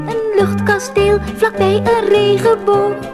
Het verwerpelijke openingsliedje (Gevaar!